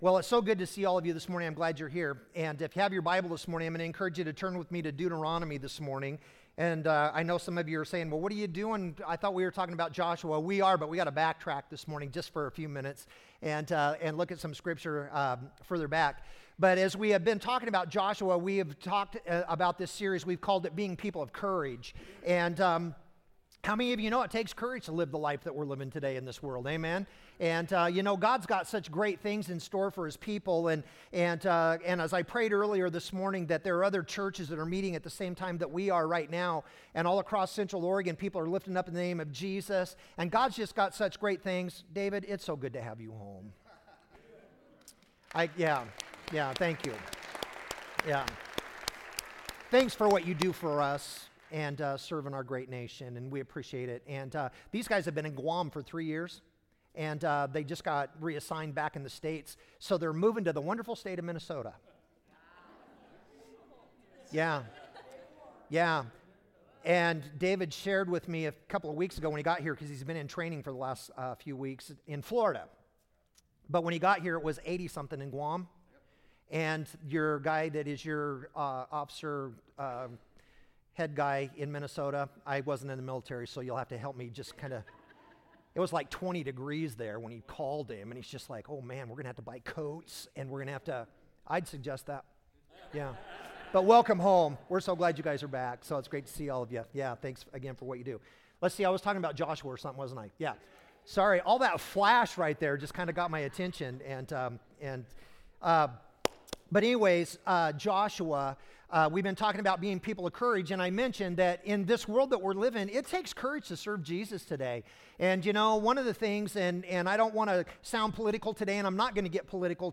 Sermons | Redmond Assembly of God